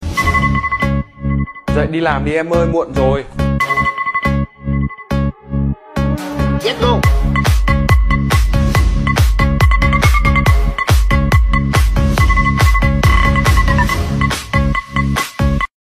Nhạc Chuông Báo Thức